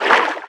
Sfx_creature_featherfish_swim_fast_04.ogg